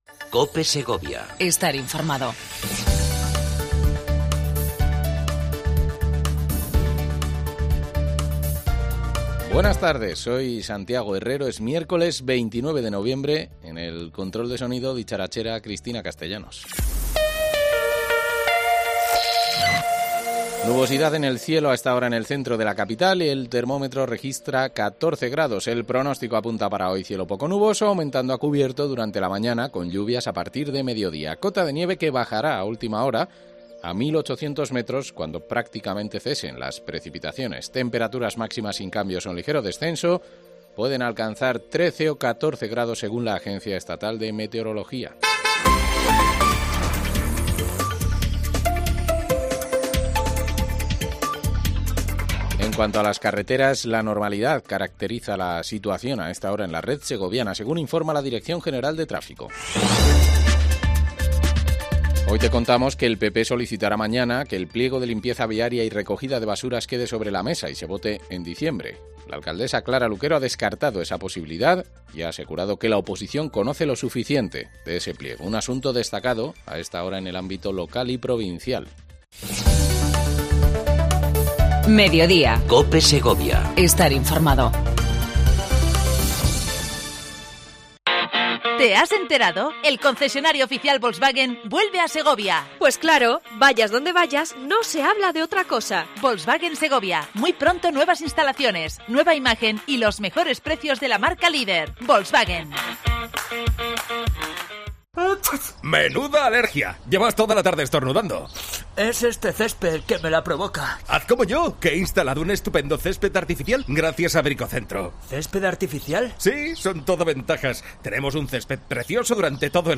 AUDIO: Entrevista con el Alcalde de Turégano Juan Montes, Feria de San Andrés